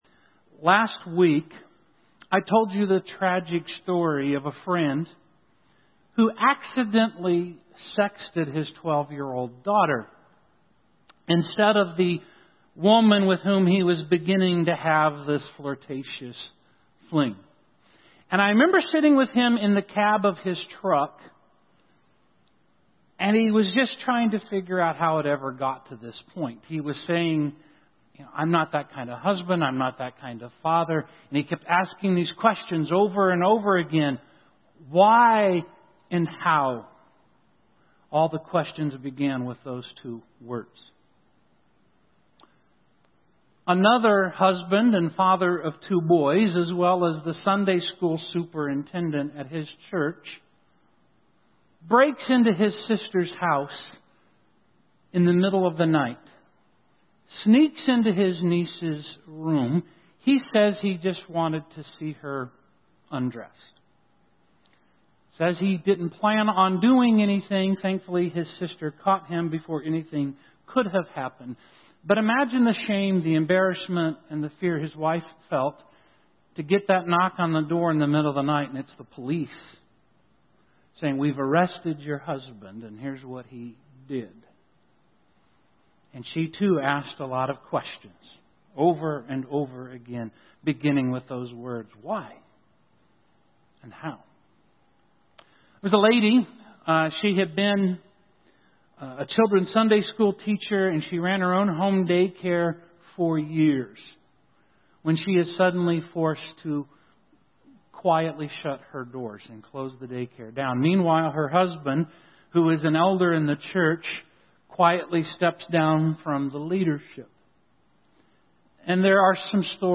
How to Bring Down a Strong Man (Part 1) — Son-Rise Christian Church, Marshfield, MO